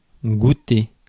Ääntäminen
France: IPA: [gu.te]